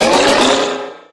Cri de Méga-Mysdibule dans Pokémon HOME.
Cri_0303_Méga_HOME.ogg